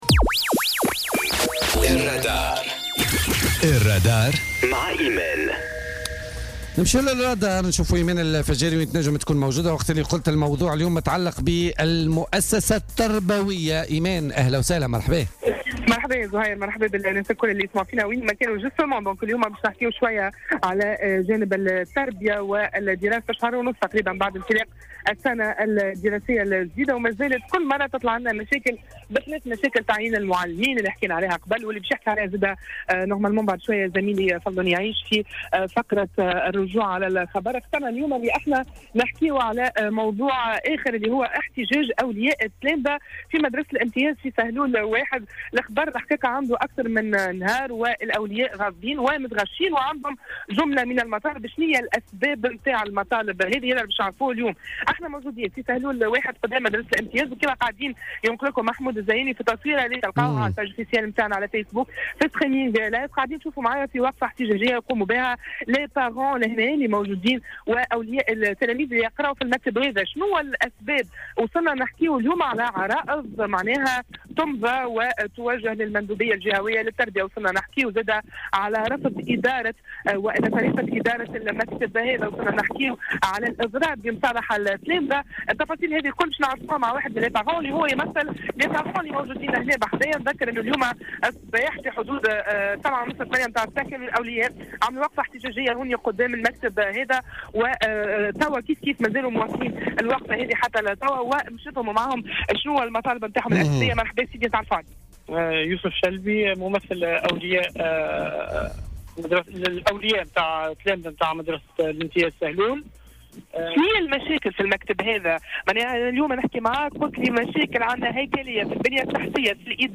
تنقل فريق "الرادار" اليوم الاثنين 23 أكتوبر 2017، إلى مدرسة الامتياز بسهلول1، اثر تنفيذ حوالي 60 وليا وقفة احتجاجية أمام المدرسة.